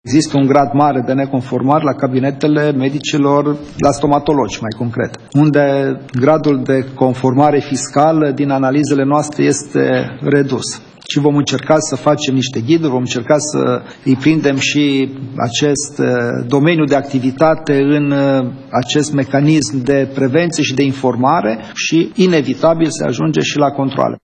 Președintele Agenției Naționale de Administrare Fiscală, Lucian Heiuș:
10nov-17-Heius-despre-dentisti.mp3